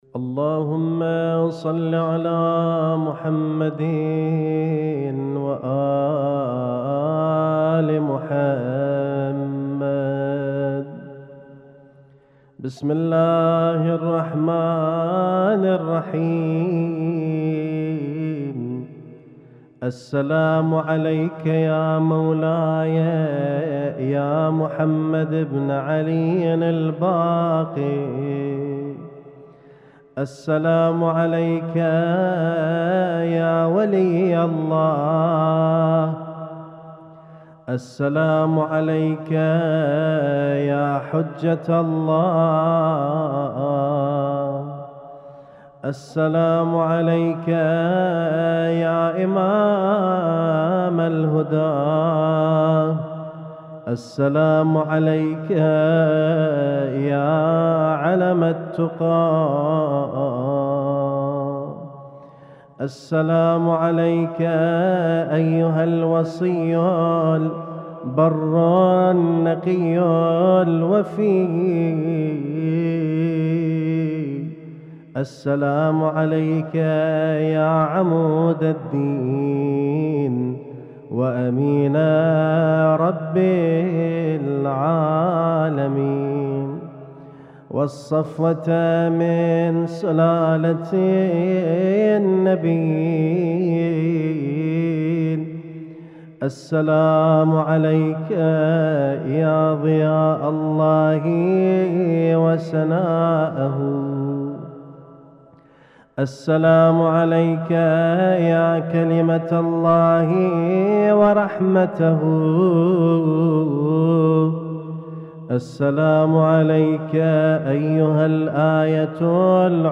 اسم التصنيف: المـكتبة الصــوتيه >> الزيارات >> الزيارات الخاصة